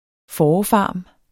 Udtale [ ˈfɒːɒ- ]